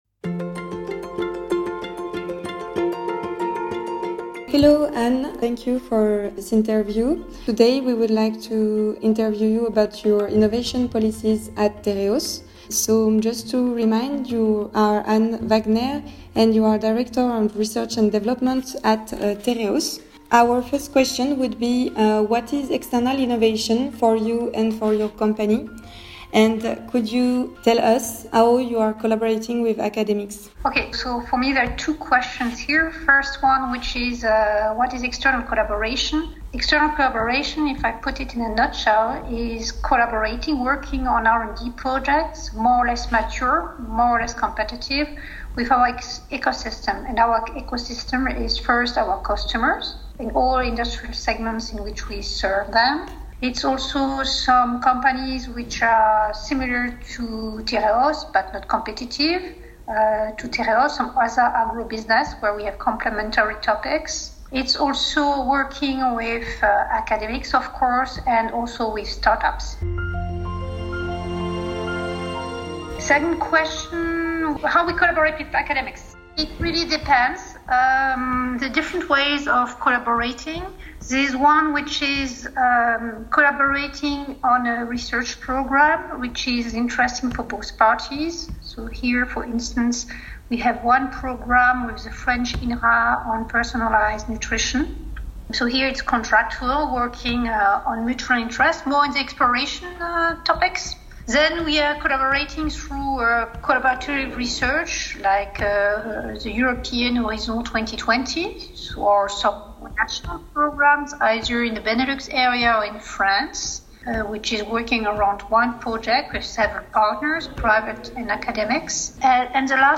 Interview-Tereos-Final.mp3